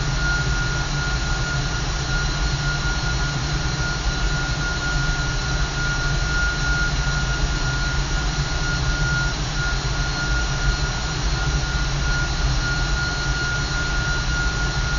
A320-family/Sounds/SASA/CFM56B/cockpit/cfm-spool.wav at e56672d06bc7cf37bc236517d2f44e46a309d9fb